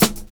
2511R SN.wav